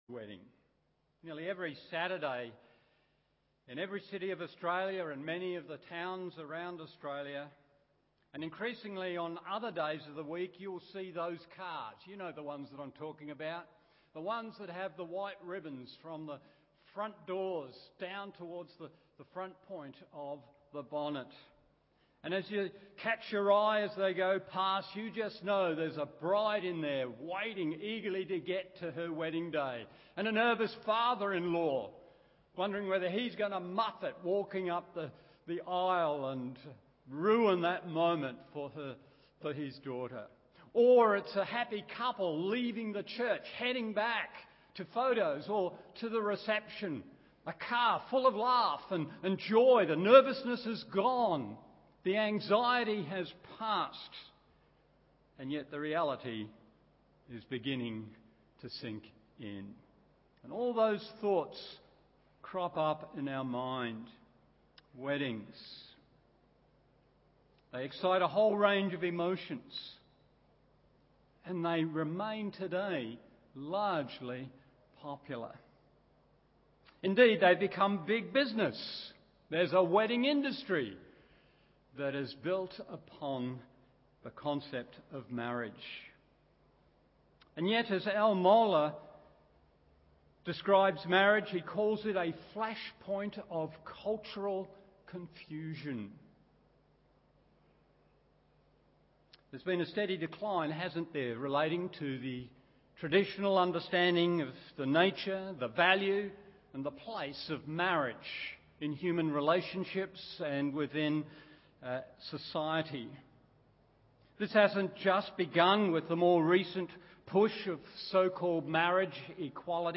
Morning Service Gen 1:26-28 1. Marriage is God’s Idea 2. Marriage is God’s Design 3. God’s Big Idea in Marriage…